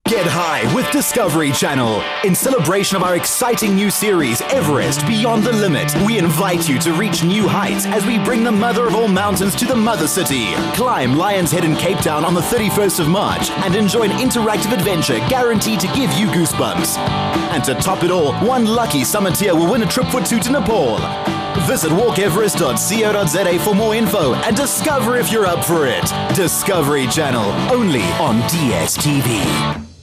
Masculino
Inglês - Sul Africano